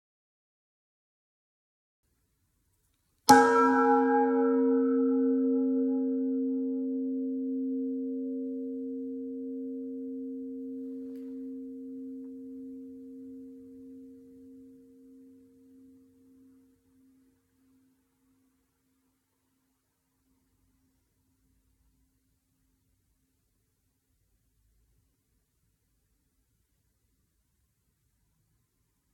Die verschiedenen Teiltöne der Glocken hört man an allen Anschlagspunkten, jedoch in jeweils unterschiedlicher Intensität.
Anschlagpunkt c [512 KB]
glocke-brauweiler-c.mp3